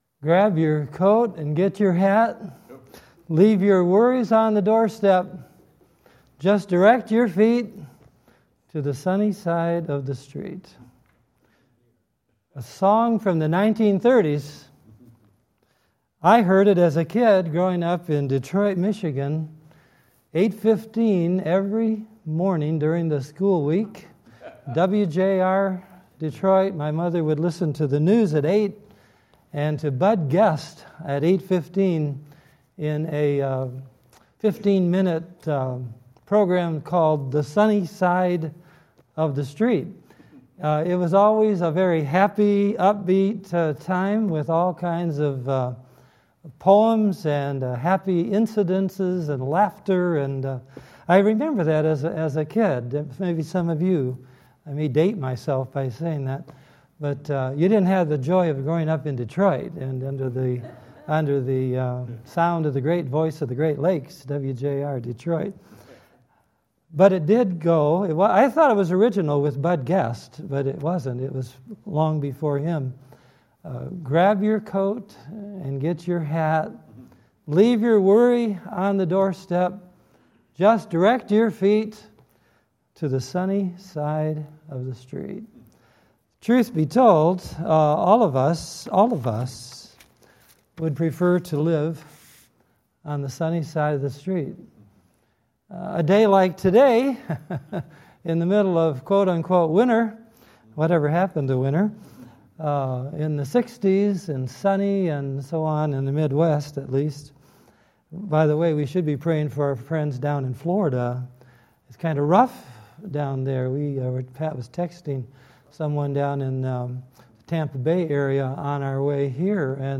2017 Categories Sunday Evening Message Download Audio 1 Kings 17:17-24 Previous Back Next